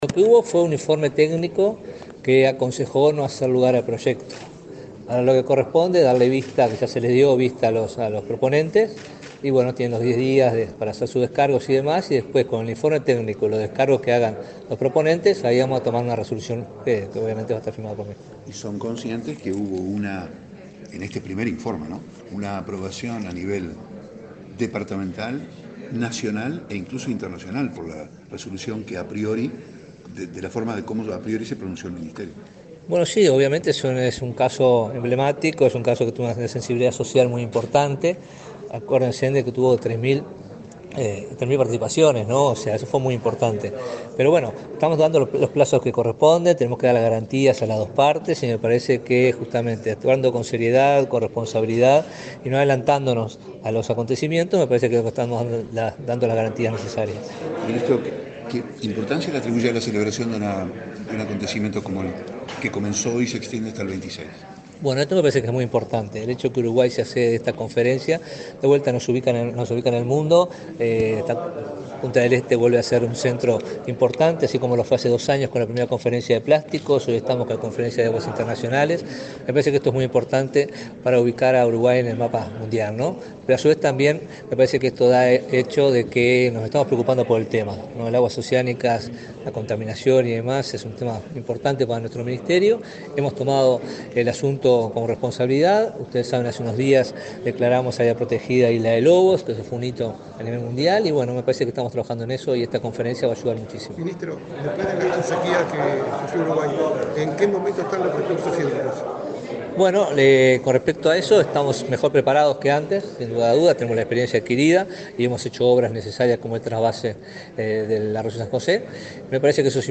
Declaraciones del ministro de Ambiente, Robert Bouvier
El ministro de Ambiente, Robert Bouvier, dialogó con la prensa, luego de participar en la apertura de la Décima Conferencia Bienal de Aguas